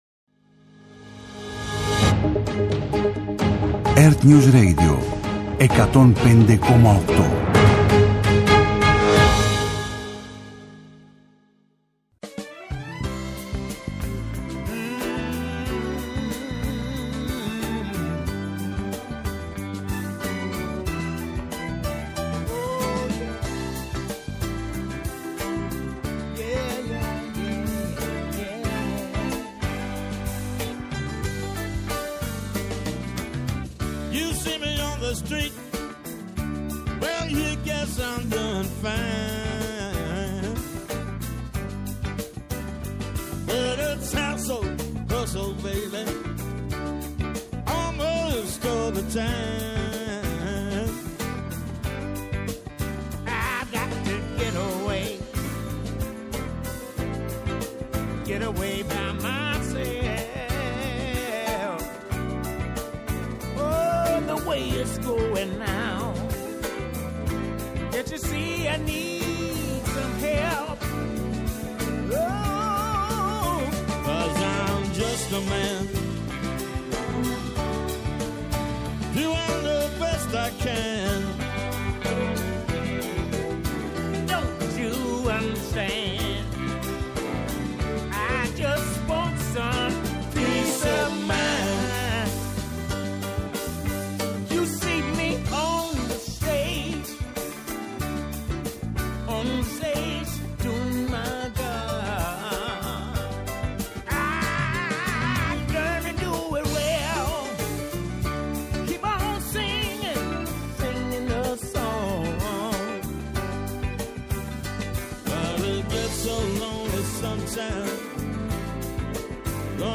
-Απόσπασμα από την ενημέρωση των πολιτικών συντακτών από τον Κυβερνητικό Εκπρόσωπο, Παύλο Μαρινάκη